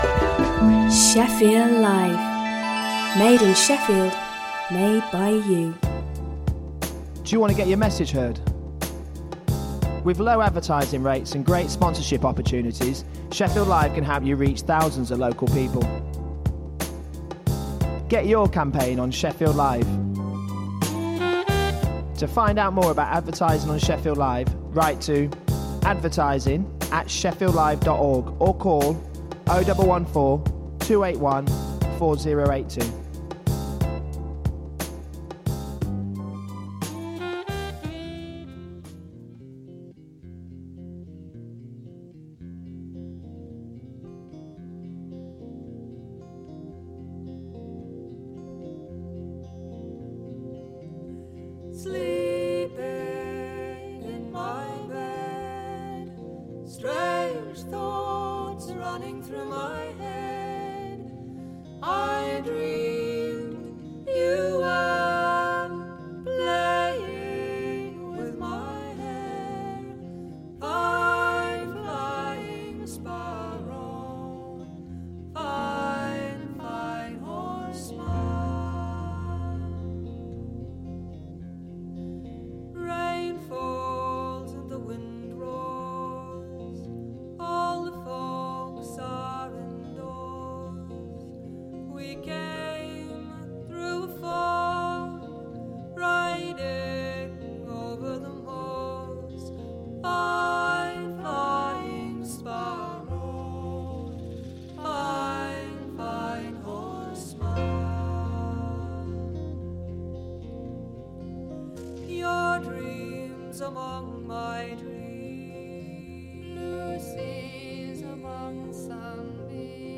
Come and join us to hear brilliant music that is not often played on other radio stations today.